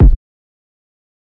KICK 16.wav